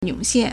湧现 (湧現) yǒngxiàn
yong3xian4.mp3